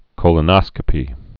(kōlə-nŏskə-pē)